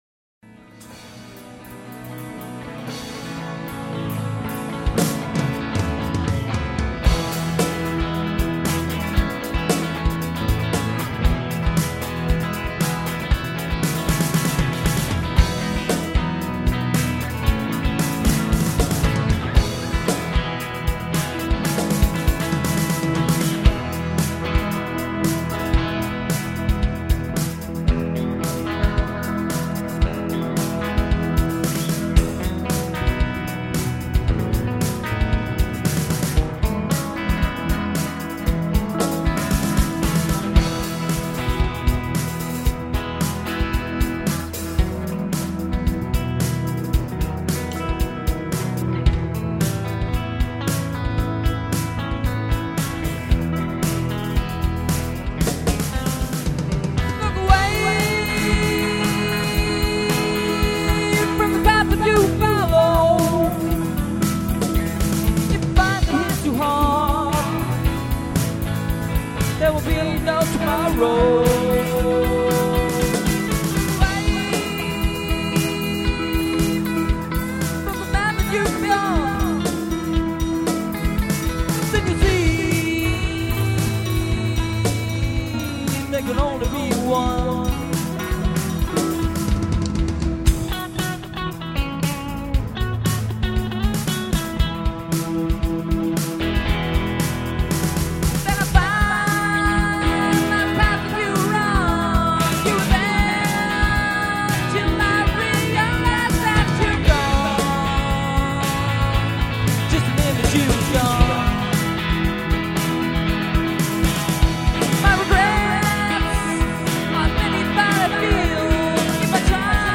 original improv jam